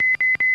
Radar Warning Receiver